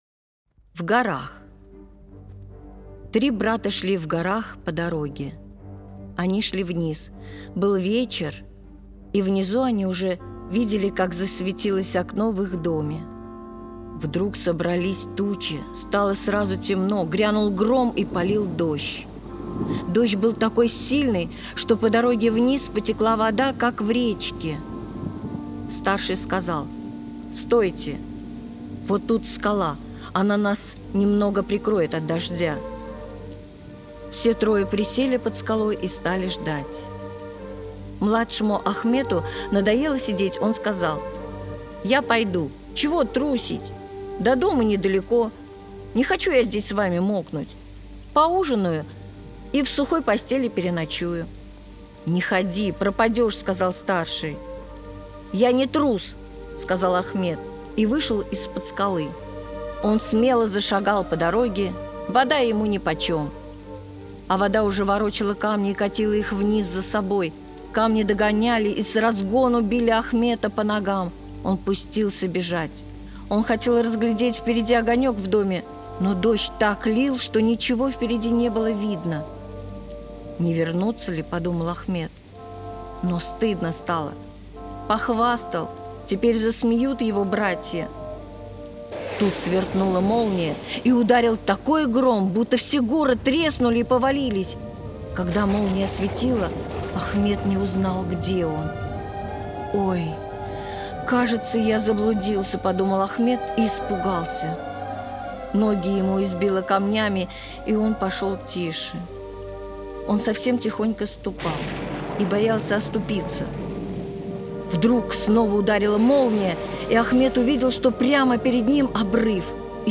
Аудиорассказ «В горах»